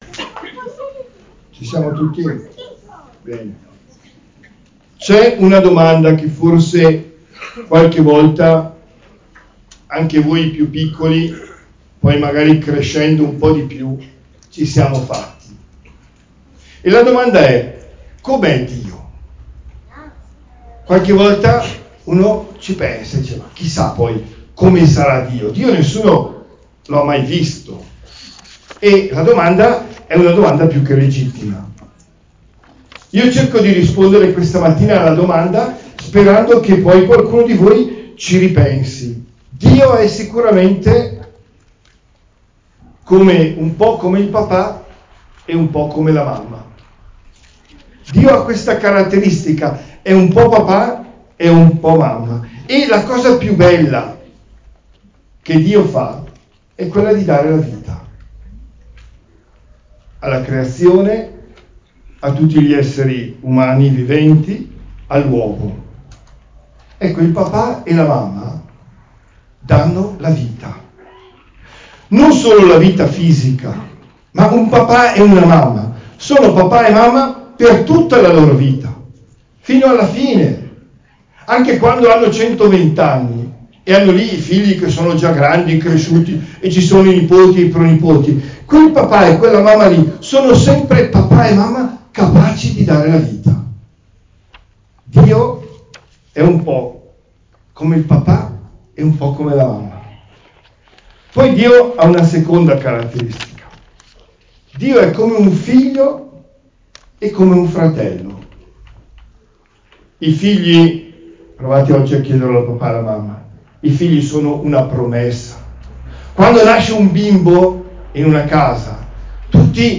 OMELIA DEL 26 MAGGIO 2024